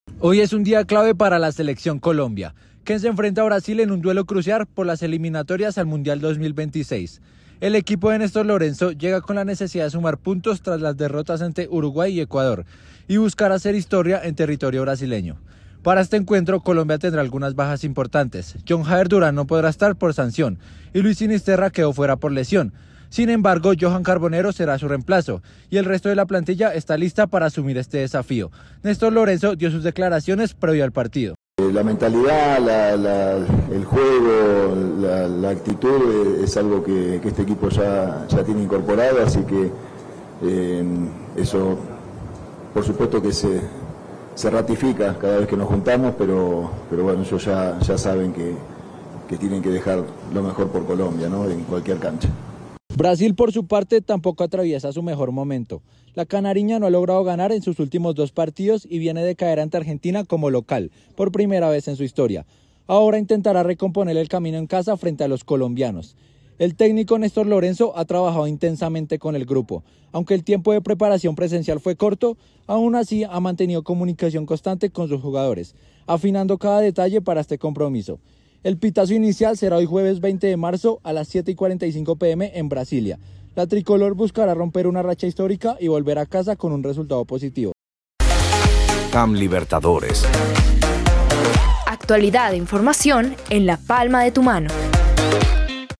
NOTA-RADIAL-futbol-mp3.mp3